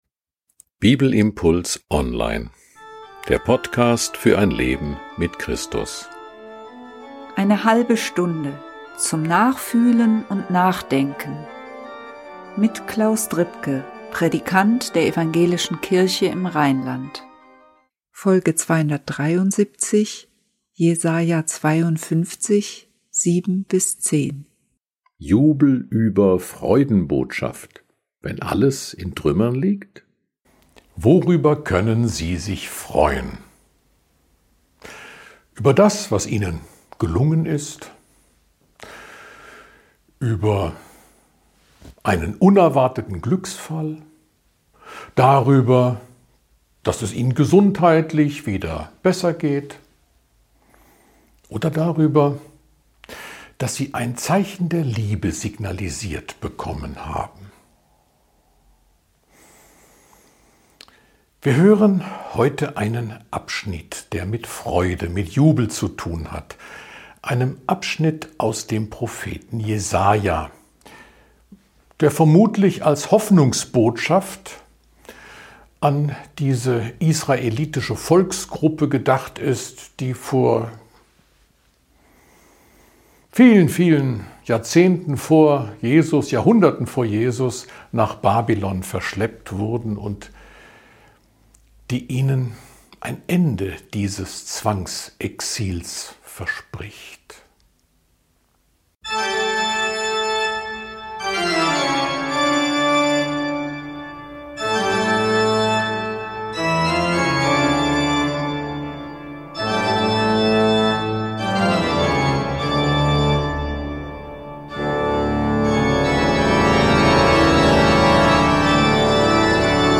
Ein Bibelimpuls zu Jesaja 52, 7-10.